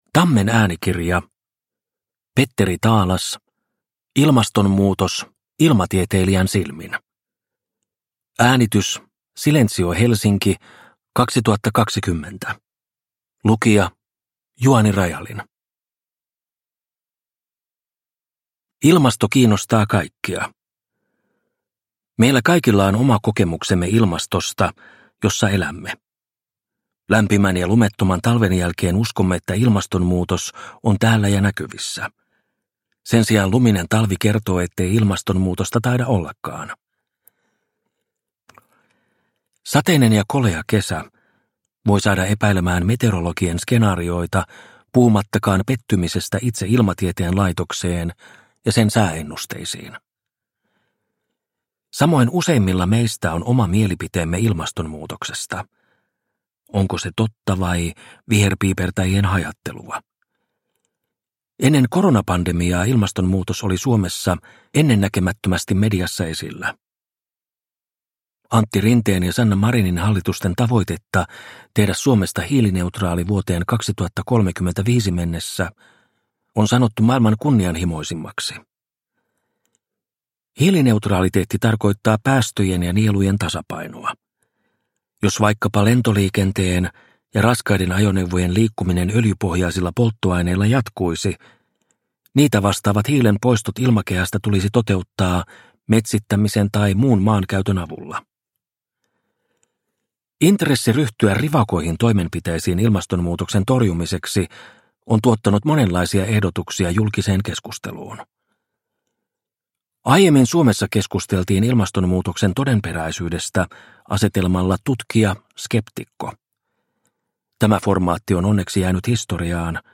Ilmastonmuutos ilmatieteilijän silmin – Ljudbok – Laddas ner